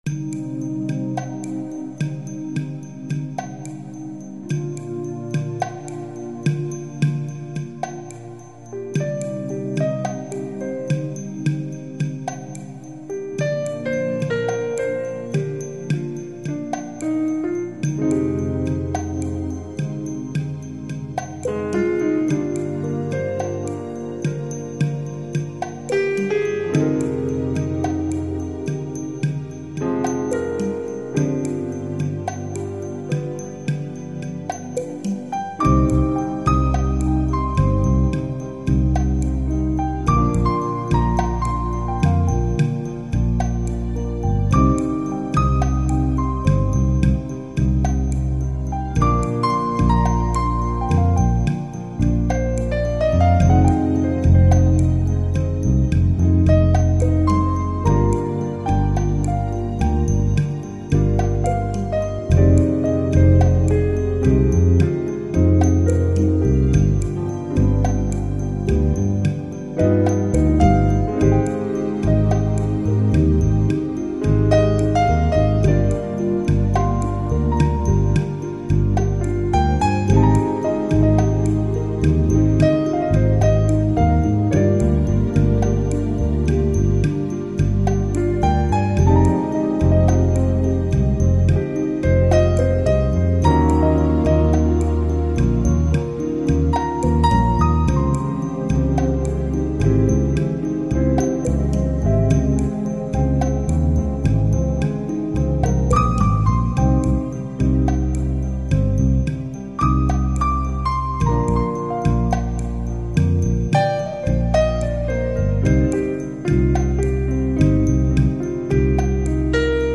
Synth Compositions